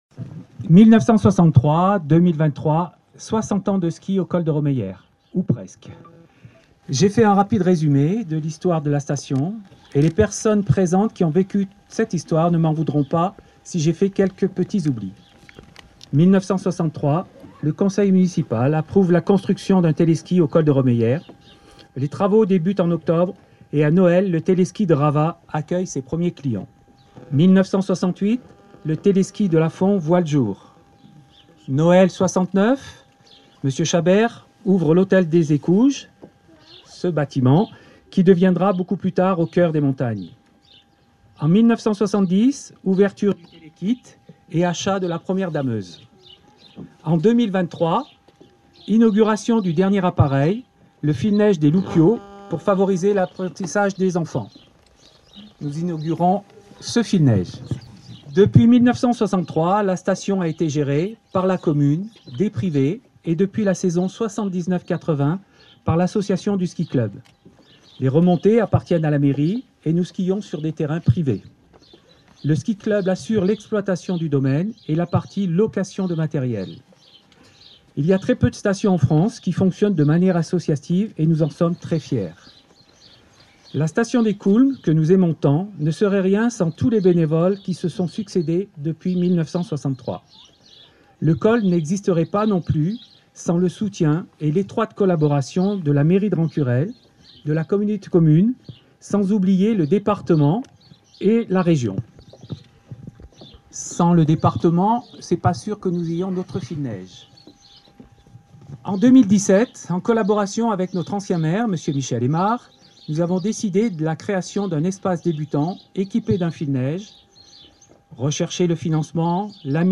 Prises de parole le samedi 18 février 2023 au col de Romeyère des acteurs associatifs et élus du territoire avant de couper le cordon d’inauguration.
Discours-anniversaire-domaine-des-Coulmes.mp3